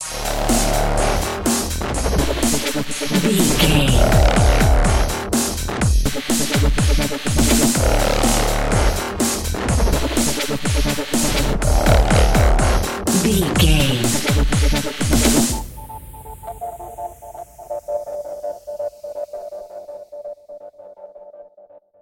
Fast paced
Aeolian/Minor
aggressive
dark
driving
energetic
intense
piano
drum machine
synthesiser
breakbeat
synth leads
synth bass